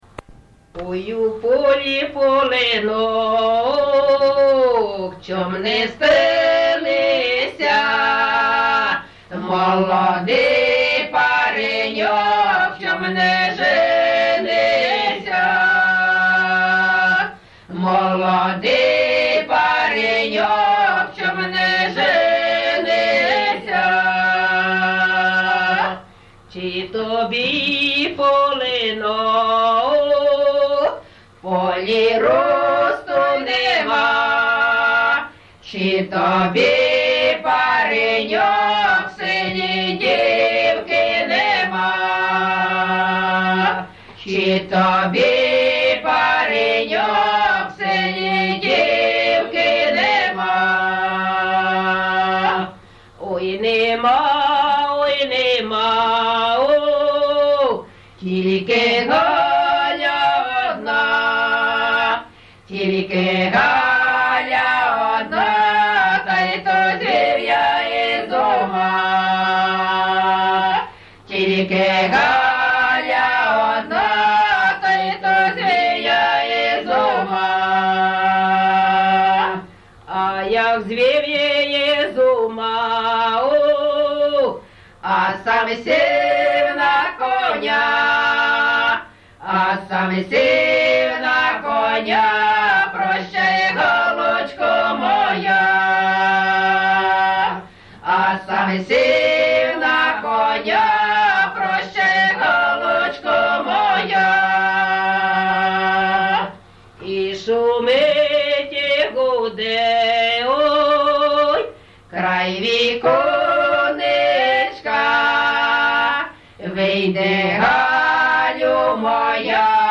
ЖанрПісні з особистого та родинного життя
Місце записус. Почапці, Миргородський район, Полтавська обл., Україна, Полтавщина